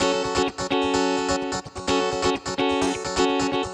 VEH3 Electric Guitar Kit 1 128BPM